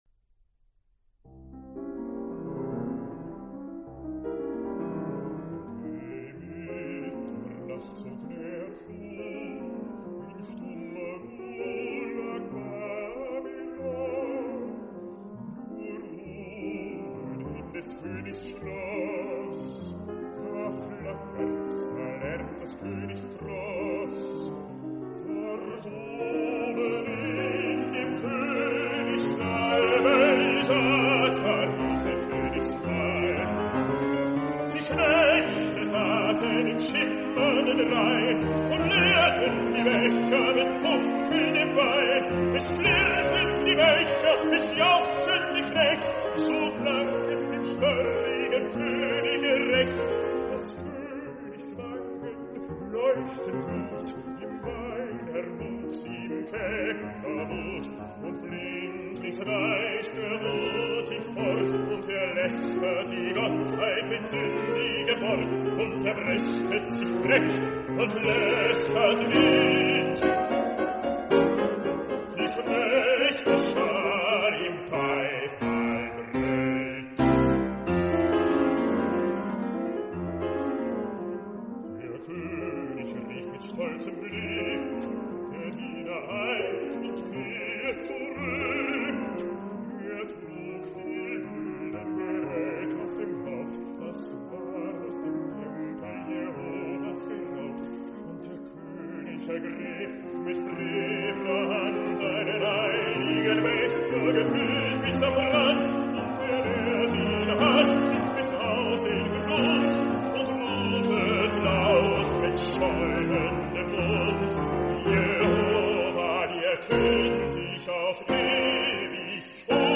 dramatic, declamatory setting